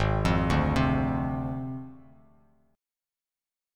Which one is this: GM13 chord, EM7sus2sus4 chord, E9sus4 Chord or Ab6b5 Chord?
Ab6b5 Chord